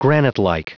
Prononciation du mot granitelike en anglais (fichier audio)